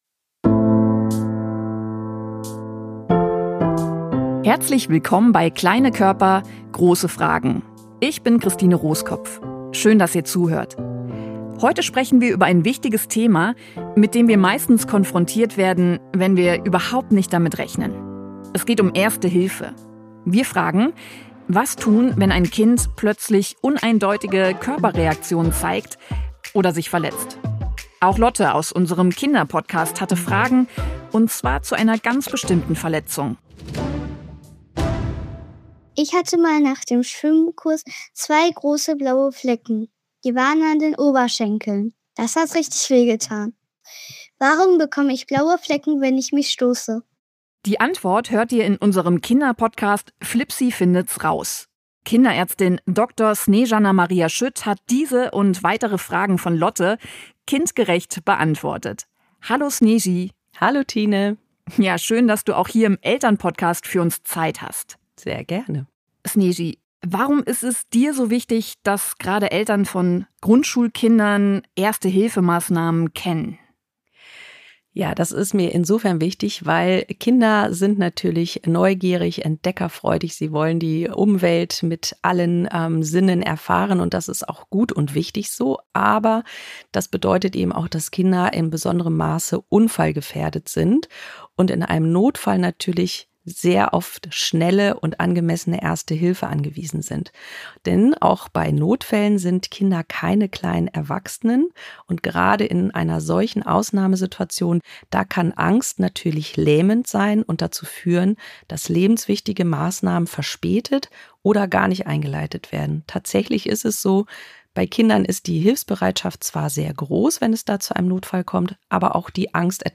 spricht